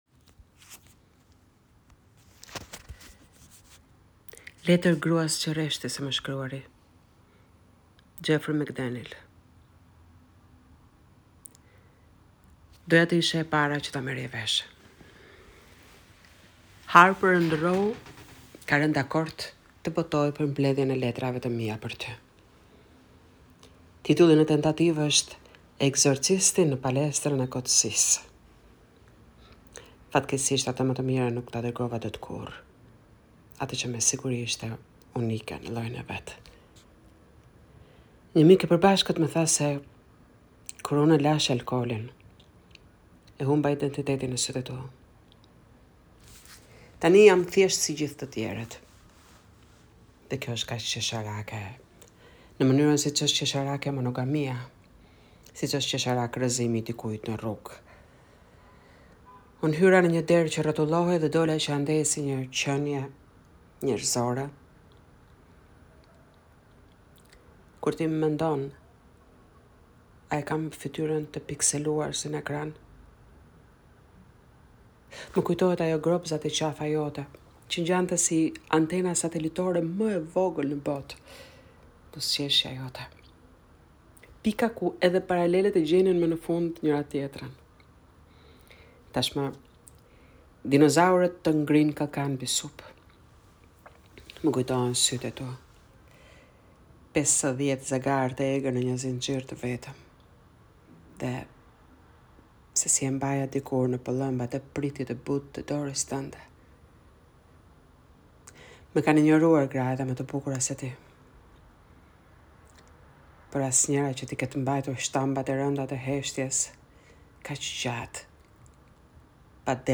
Poezia e lexuar